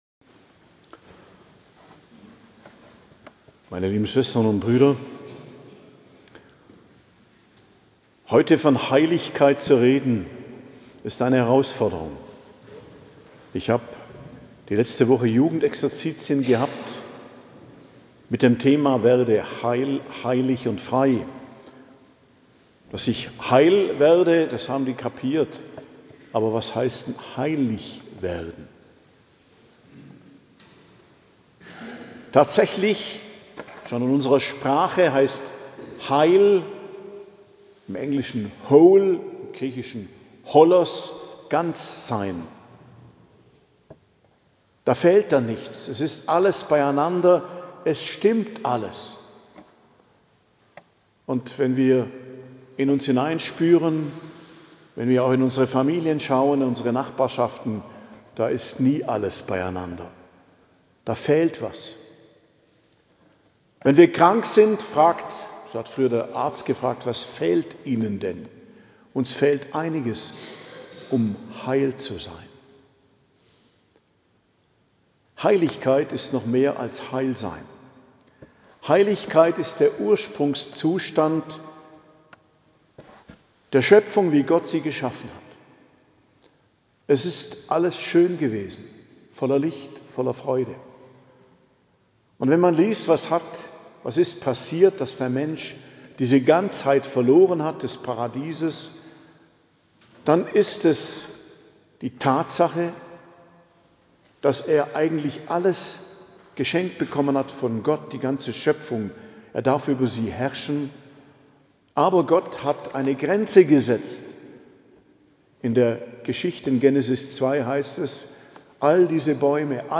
Predigt am Hochfest Allerheiligen, 1.11.2025 ~ Geistliches Zentrum Kloster Heiligkreuztal Podcast